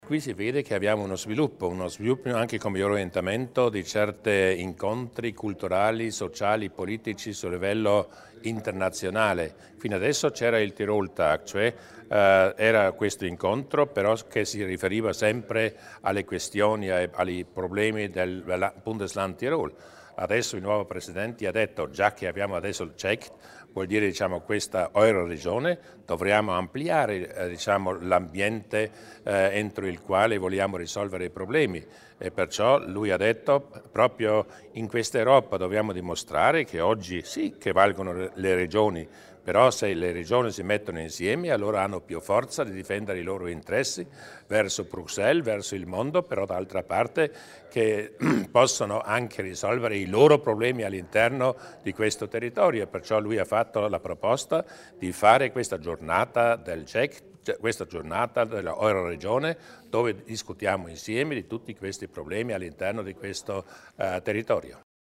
Il Presidente Durnwader spiega l'importanza delle varie iniziative in ambito Euregio